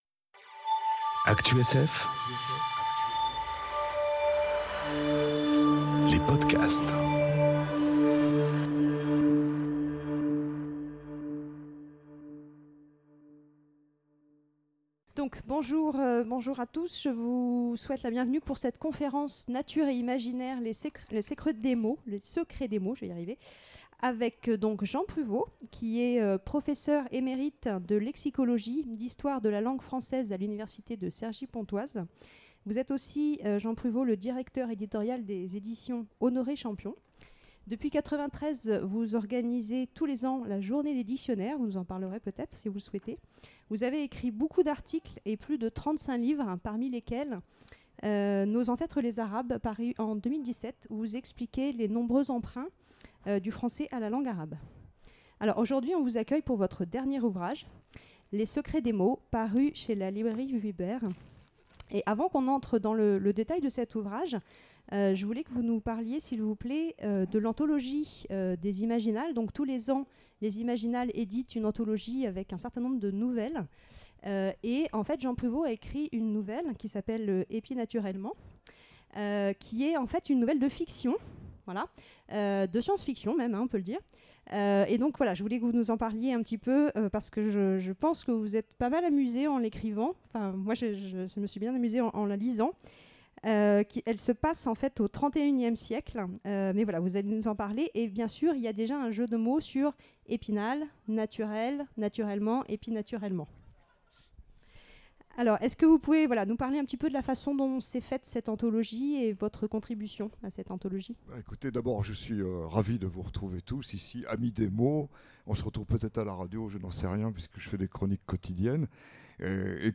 le 02/07/2019 Partager Commenter Imaginales 2019 : Nature(s) et imaginaire : Les secrets des mots.
Imaginales_2019_Natures_et_imaginaire_les_secrets_des_mots.mp3